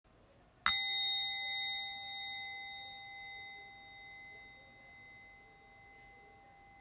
tintement-coffret.m4a